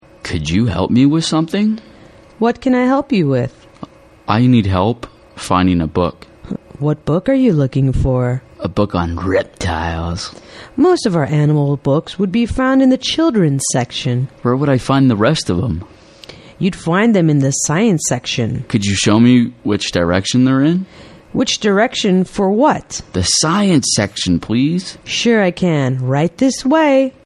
情景英语对话：Searching for a Book in the Library(1) 听力文件下载—在线英语听力室